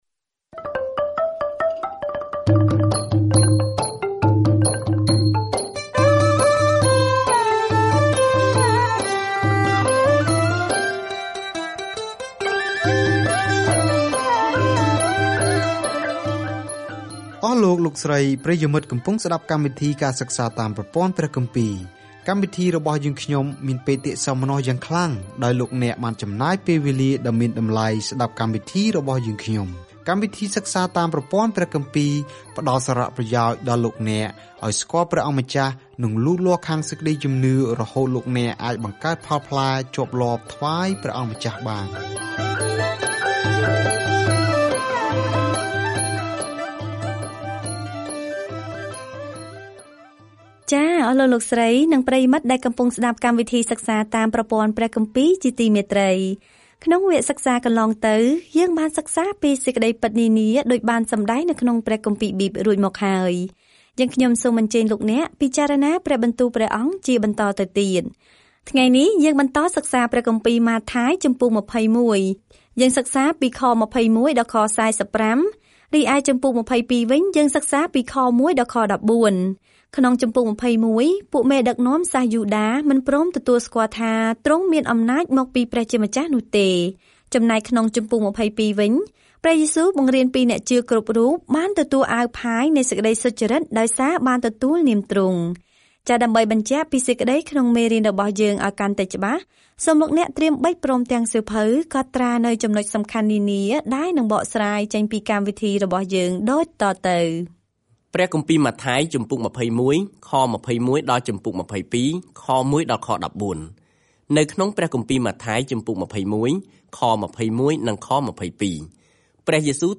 ម៉ាថាយបង្ហាញដល់អ្នកអានសាសន៍យូដានូវដំណឹងល្អថា ព្រះយេស៊ូវគឺជាព្រះមេស្ស៊ីរបស់ពួកគេ ដោយបង្ហាញពីរបៀបដែលជីវិត និងកិច្ចបម្រើរបស់ទ្រង់បានបំពេញតាមទំនាយក្នុងព្រះគម្ពីរសញ្ញាចាស់ ។ ការធ្វើដំណើរប្រចាំថ្ងៃតាមម៉ាថាយ ពេលអ្នកស្តាប់ការសិក្សាជាសំឡេង ហើយអានខគម្ពីរដែលជ្រើសរើសពីព្រះបន្ទូលរបស់ព្រះ។